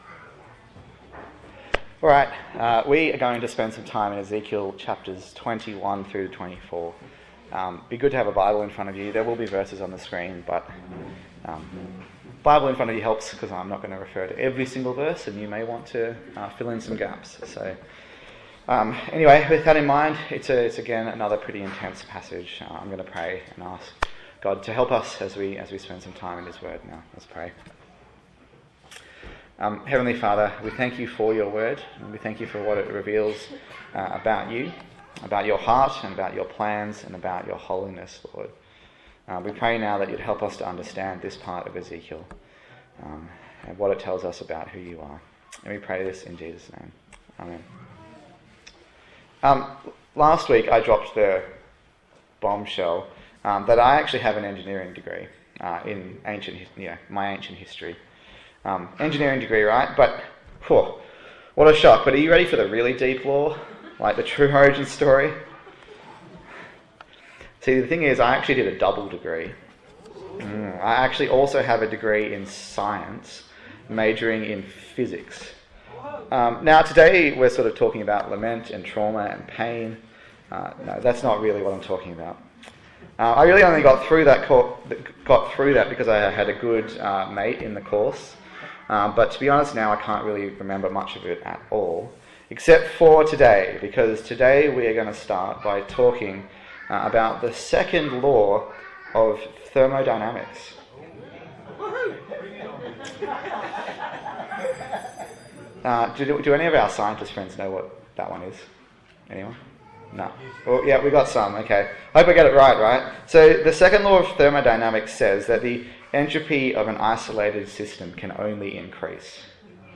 Ezekiel Passage: Ezekiel 21 to 24 Service Type: Sunday Morning